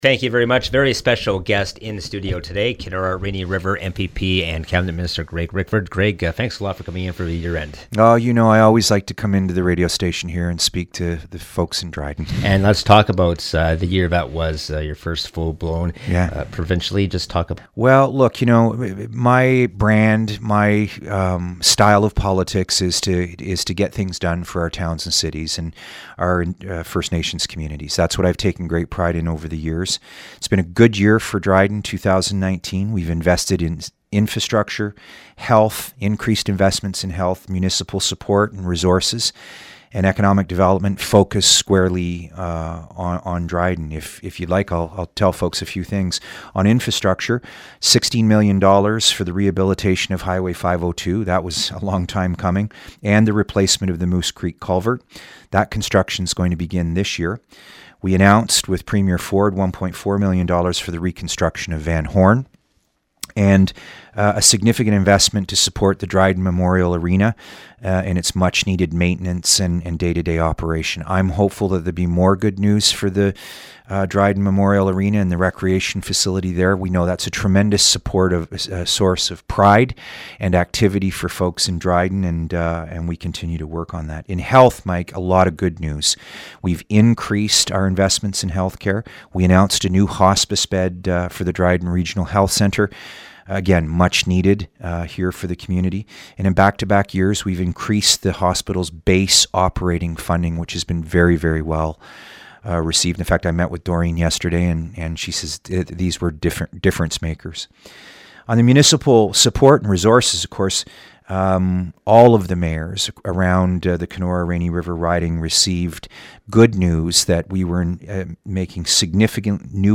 He recently visited the CKDR Newsroom for a 2019 year end interview.